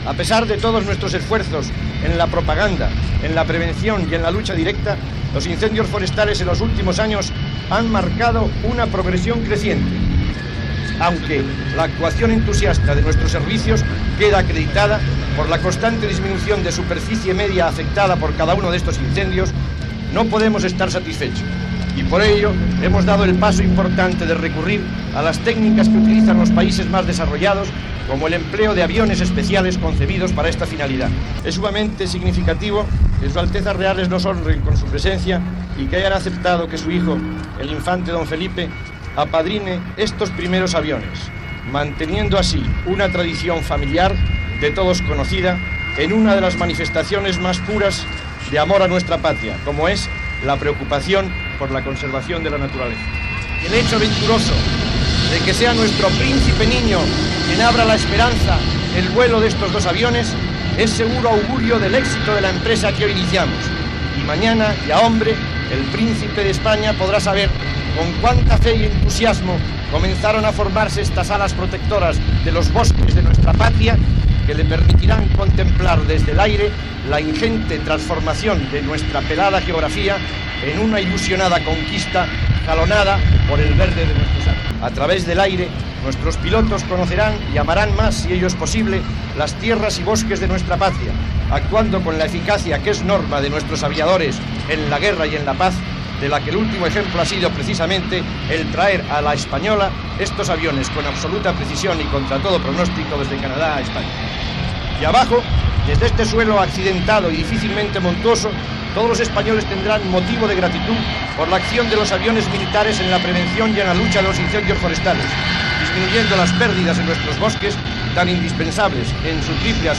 Paraules del ministre d'Agricultura Tomàs Allende y García Baxter,a l'aeroport de Madrid Barajas, amb motiu de l'arribada a Espanya dels dos primers avions cisterna per combatre els incendis
Informatiu